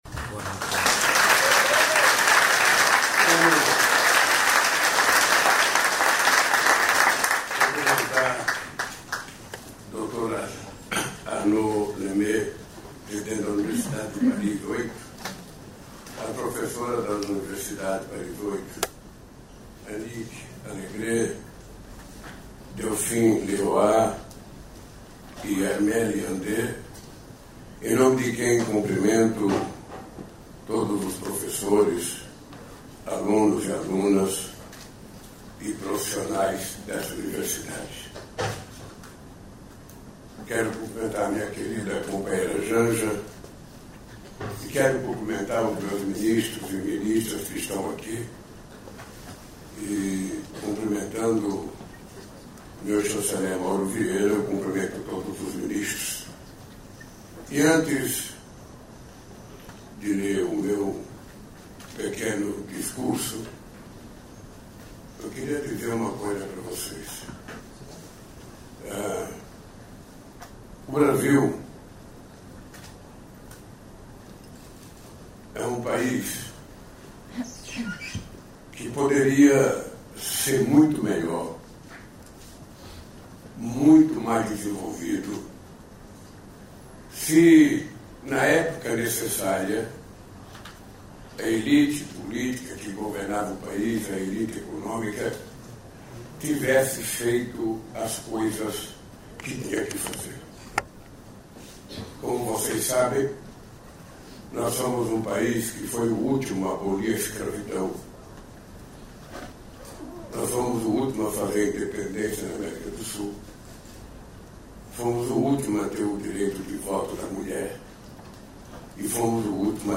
Íntegra do discurso do presidente da República, Luiz Inácio Lula da Silva, na cerimônia de entrega do Prêmio Camões de Literatura a Adélia Prado, no Palácio Itamaraty, em Brasília, nesta terça-feira (18).